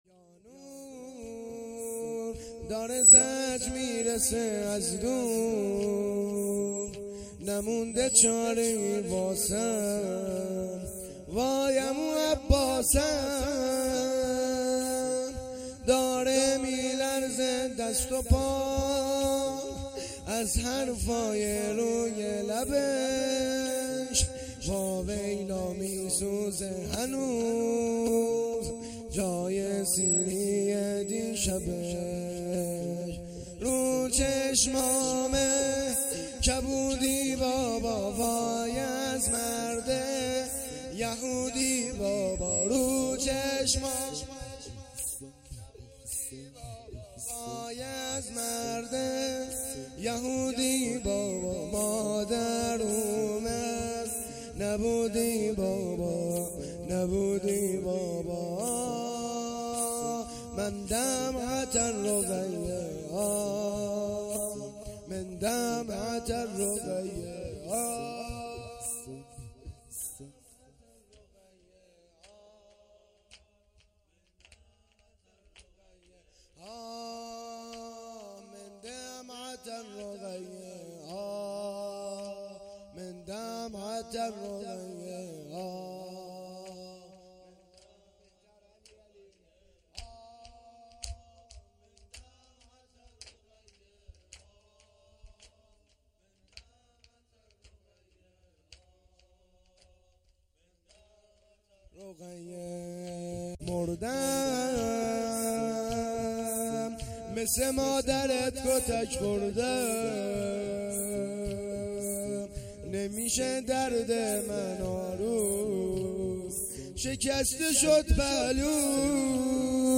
شام شهادت حضرت رقیه(س)۱۴۰۰